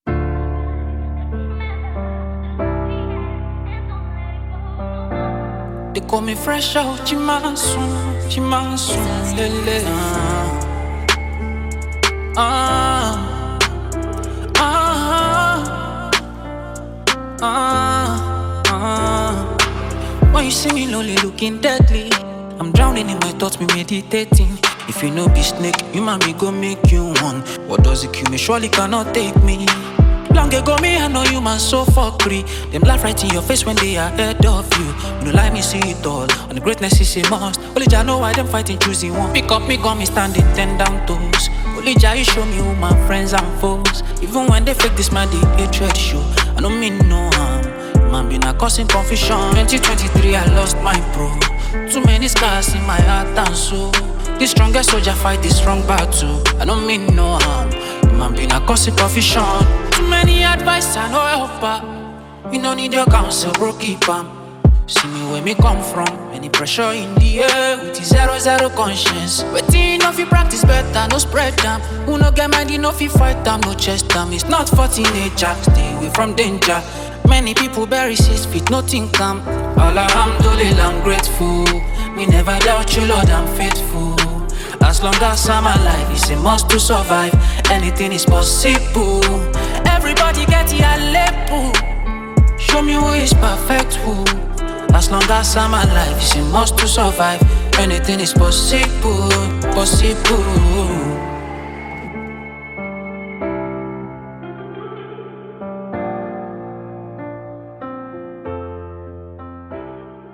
Nigerian Afro-pop artist and rapper